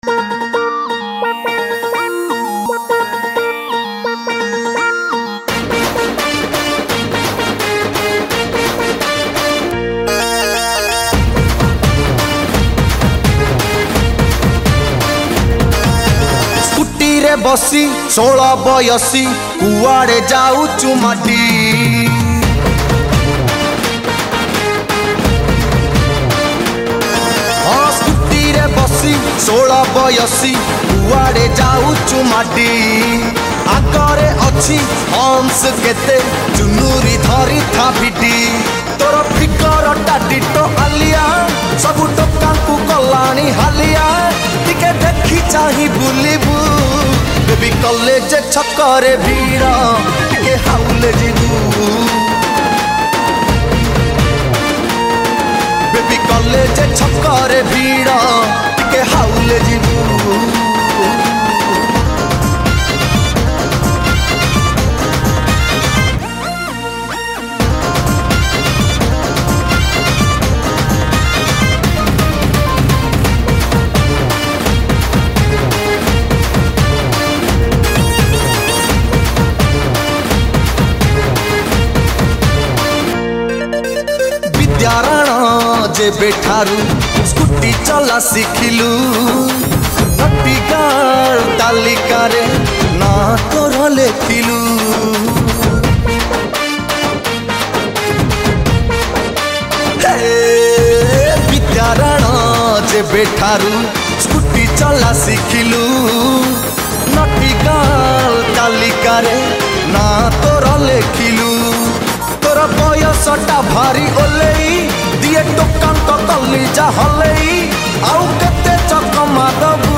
Odia Adhunika Song